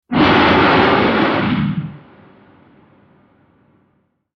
Dinosaur Roar Sound Effect
A terrifying roar of a giant dinosaur, like a Tyrannosaurus Rex or another predator. Sound of a beast roaring.
Dinosaur-roar-sound-effect.mp3